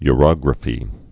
(y-rŏgrə-fē)